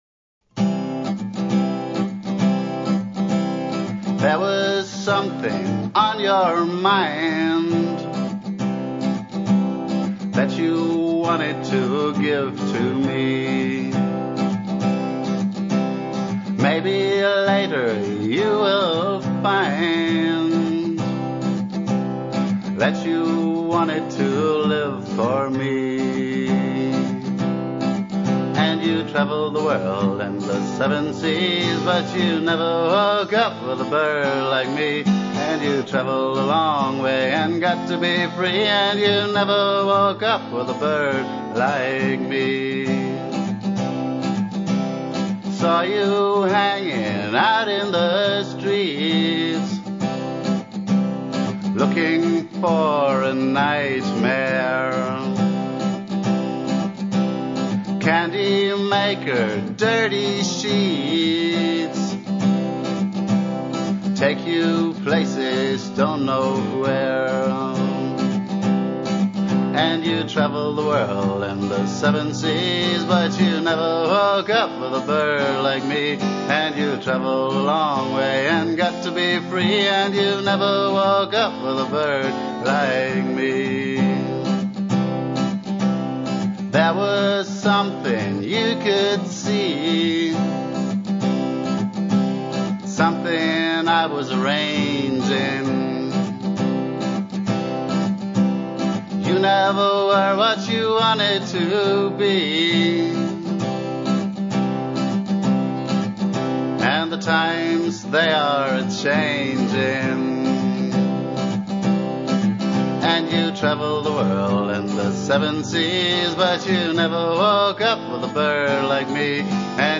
Folk, g + voc, Song # 98, mp3